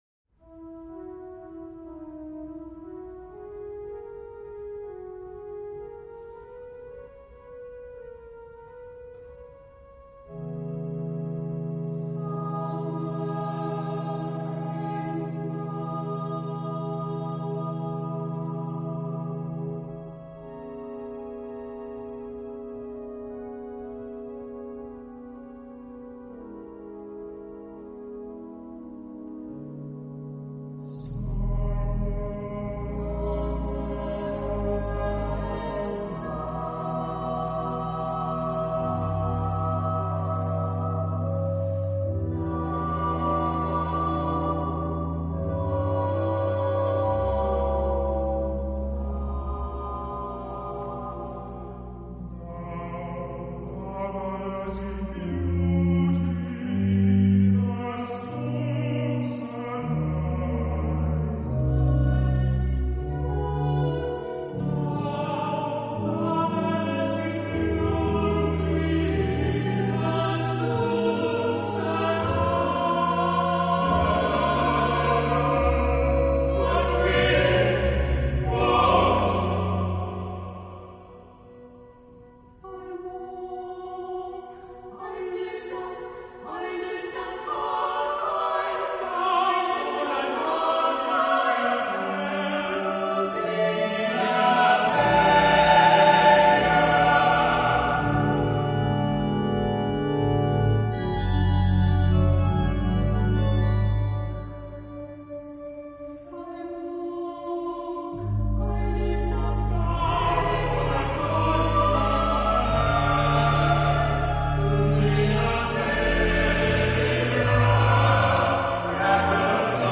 Genre-Stil-Form: weltlich ; Chor ; Gedicht
Charakter des Stückes: frei
Chorgattung: SATB (div.)  (4 gemischter Chor Stimmen )
Instrumentation: Orgel  (1 Instrumentalstimme(n))